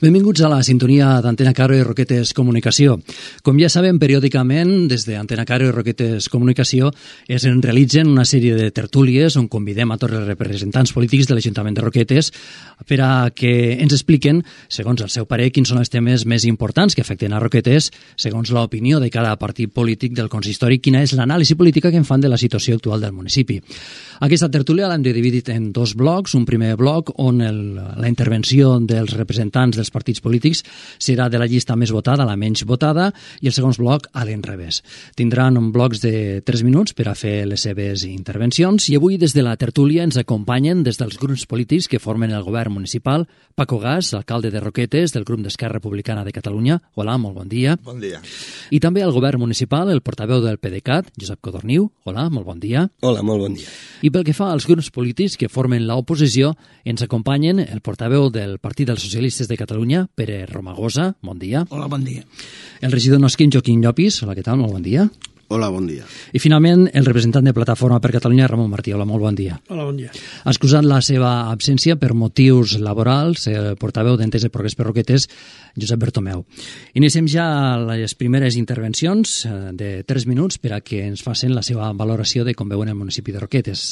Presentació de la tertúlia amb els representants dels grups polítics a l'Ajuntament de Roquetes.
Informatiu